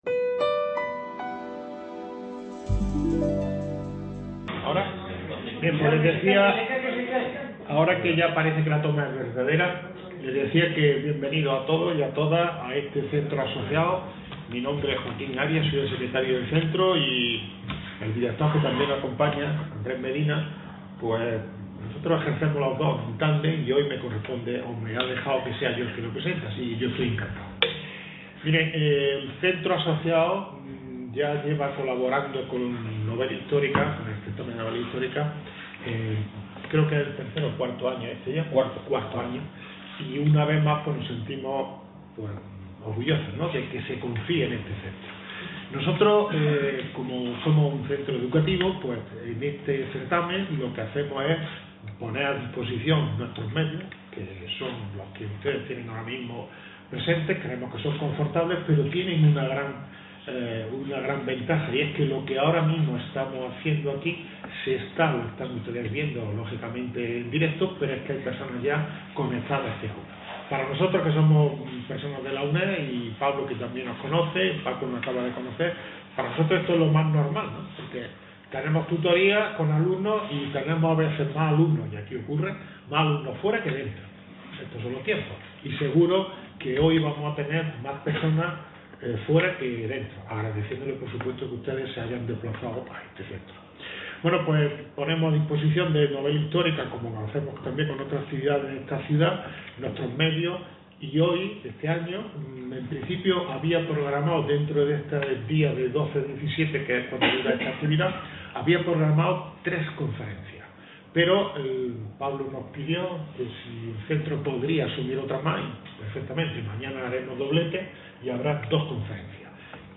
Presentación del Libro
Este Centro no sólo prestó su ayuda económica sino que también brindó su salón de actos para algunas de las charlas literarias y presentaciones de obras.